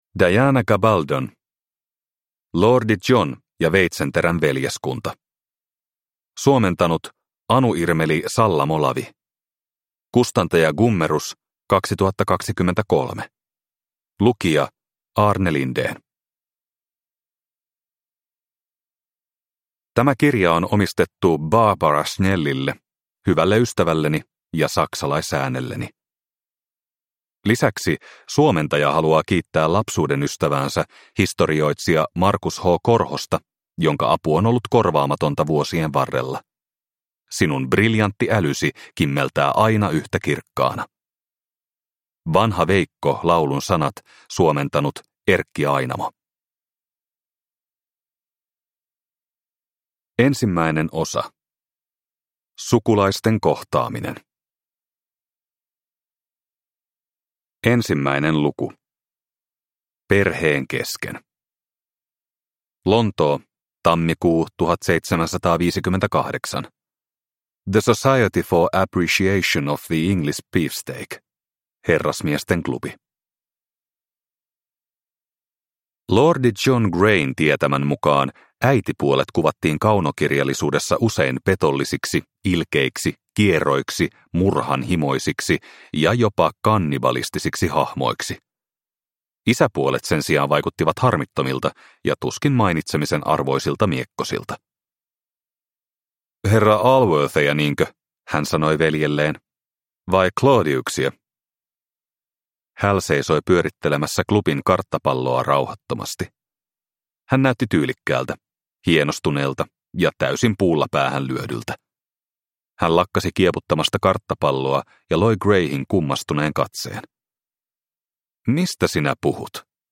Lordi John ja veitsenterän veljeskunta (ljudbok) av Diana Gabaldon